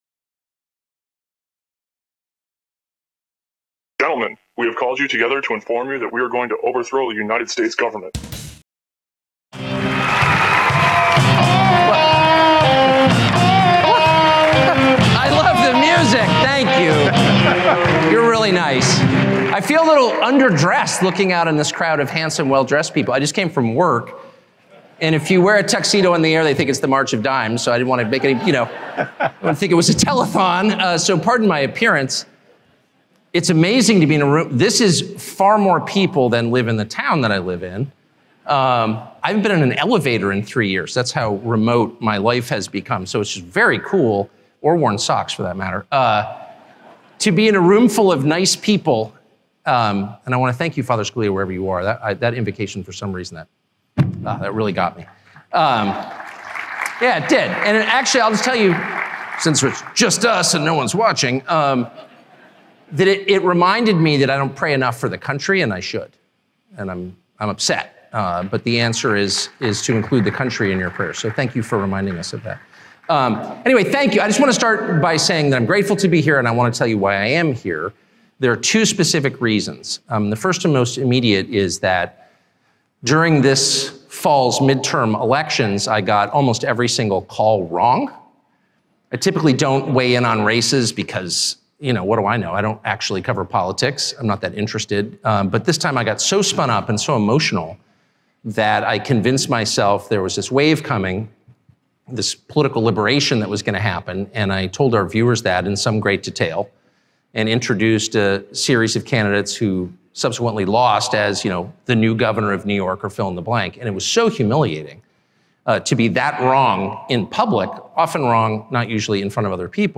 Tucker Carlson's Victory Speech before Leaving FOX